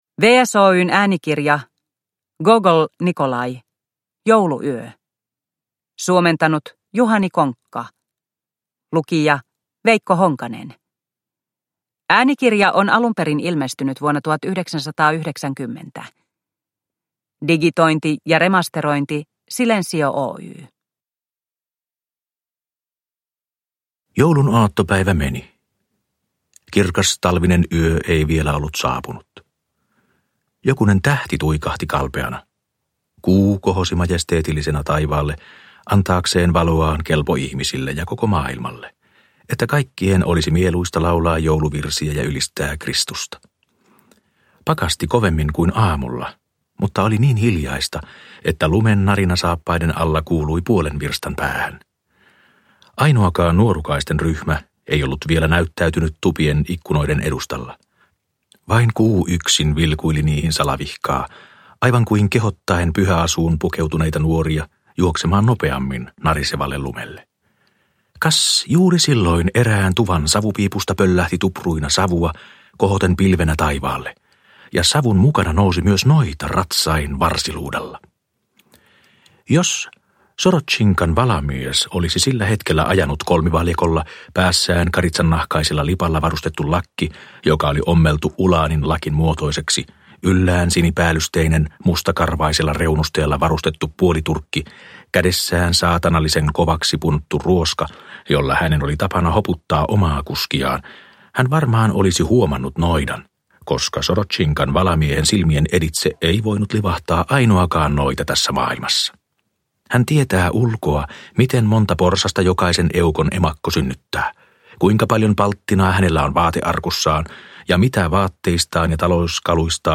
Jouluyö – Ljudbok – Laddas ner